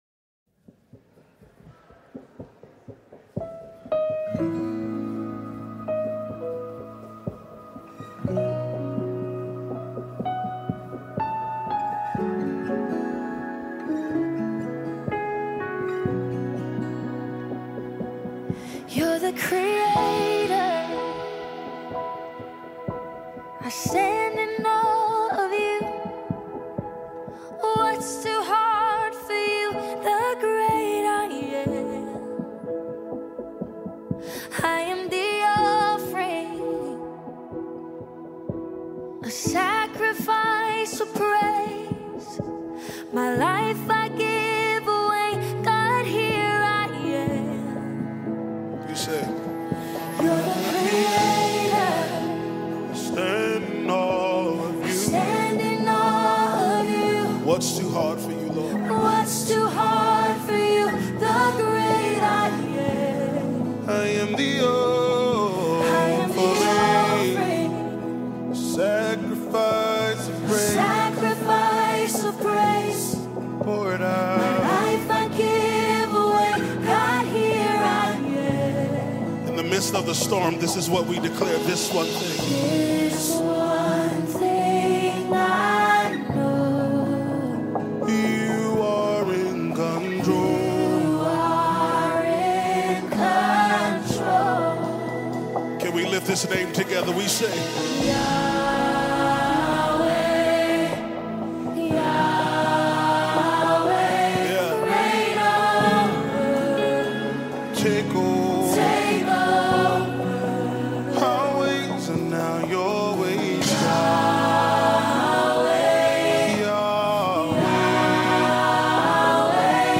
powerful prraise song
contemporary gospel